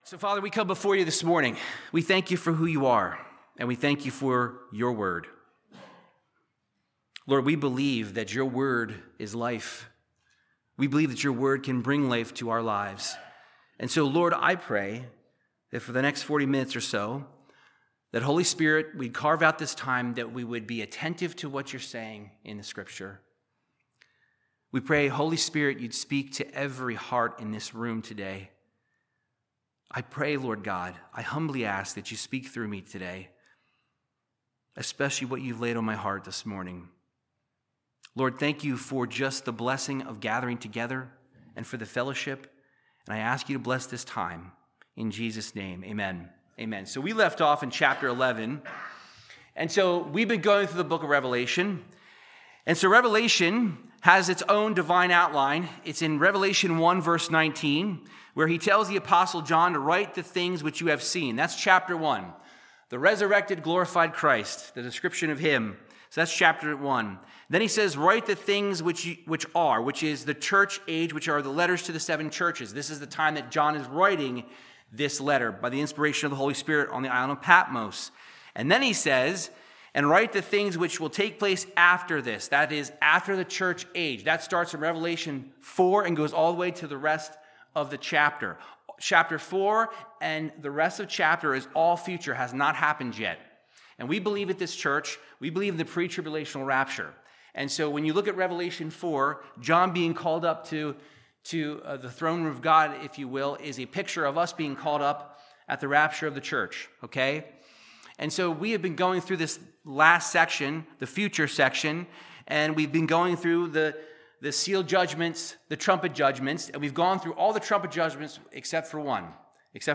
Verse by verse Bible teaching through the book or Revelation chapter 11